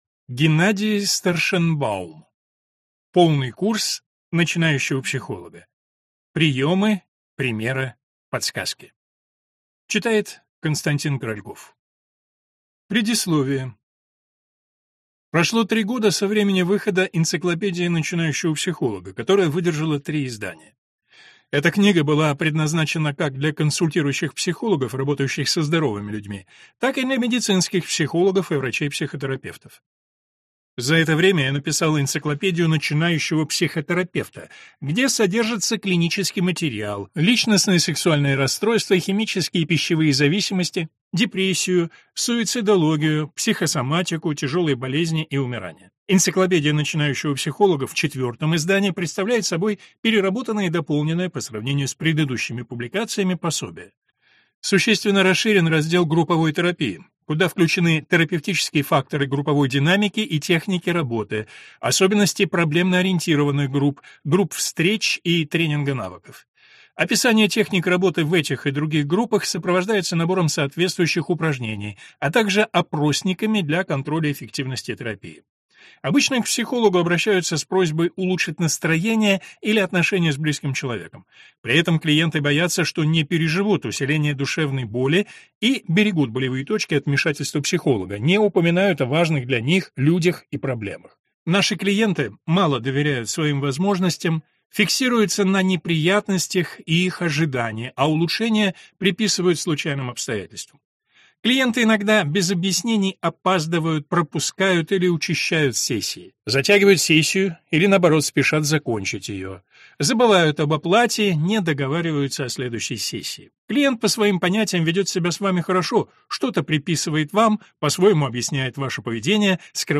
Аудиокнига Полный курс начинающего психолога. Приемы, примеры, подсказки | Библиотека аудиокниг